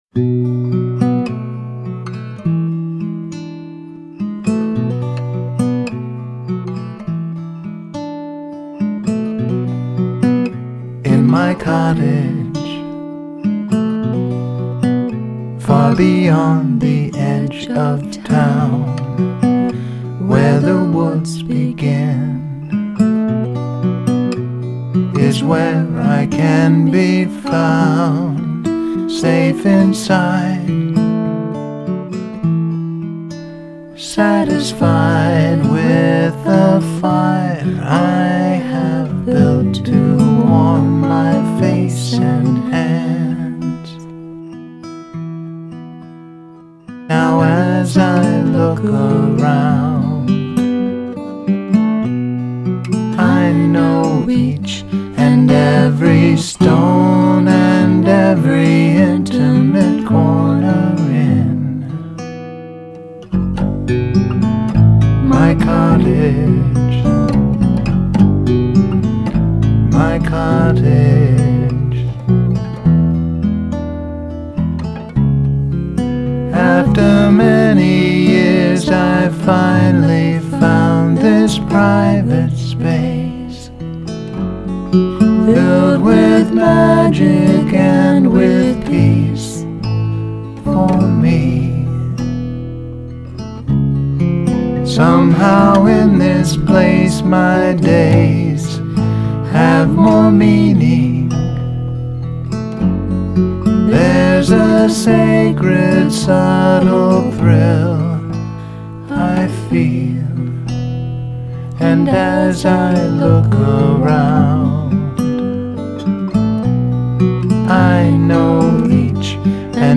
guitar, vocals and production